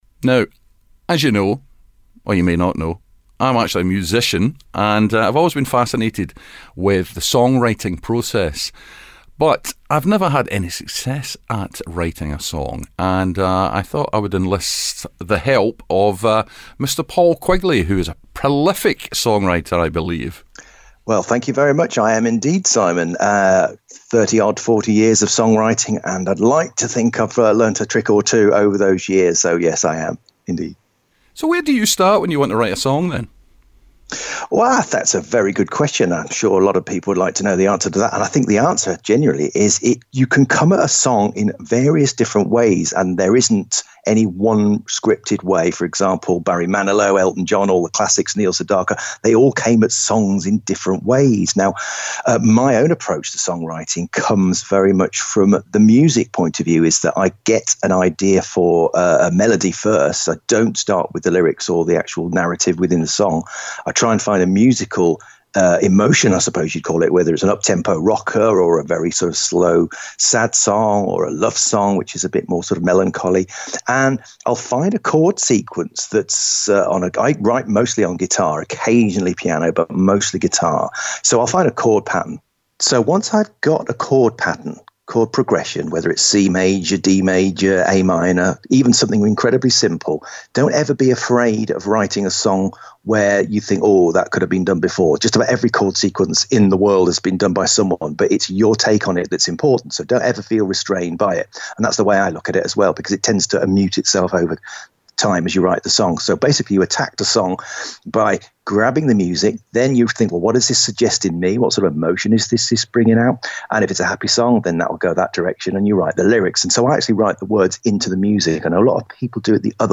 chats to us about song writing.